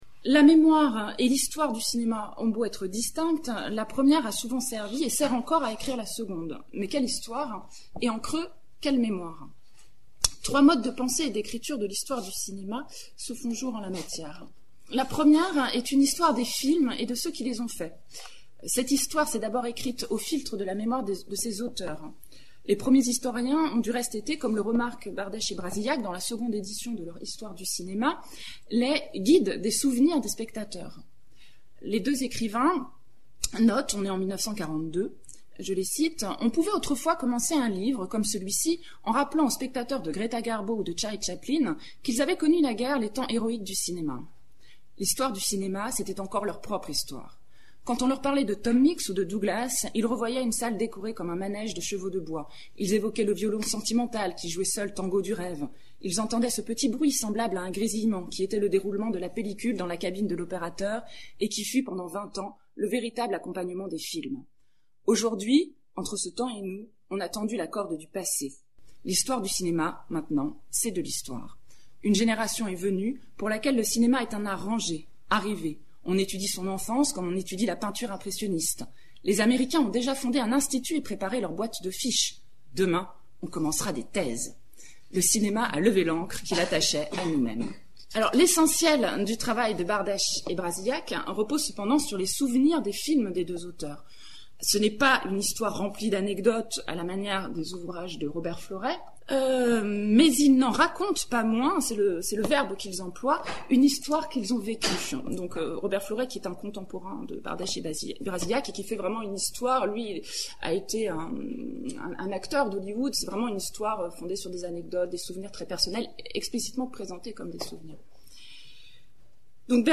Cette communication avait pour objet d’introduire la journée en interrogeant les usages des souvenirs des films dans le champ de la recherche et les enjeux de leur étude au travers des récits de spectateurs.